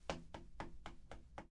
靴子
描述：脚踏在地上
Tag: 脚步